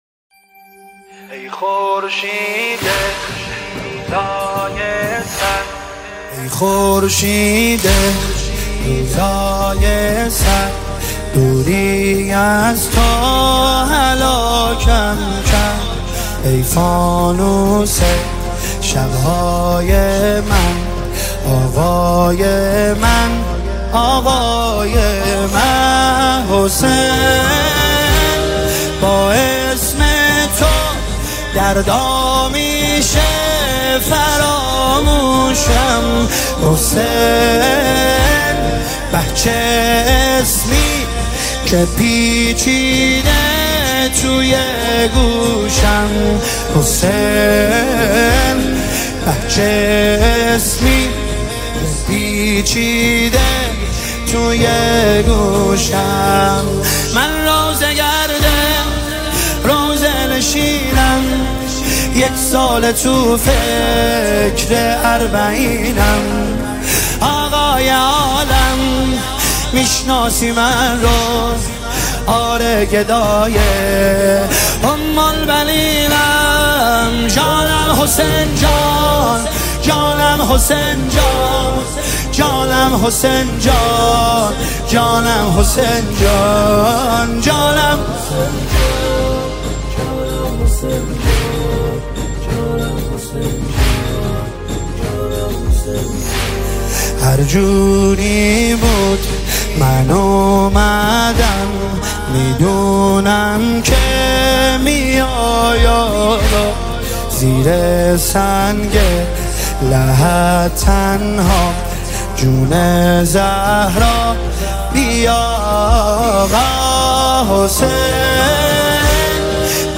نماهنگ دلنشین
مداحی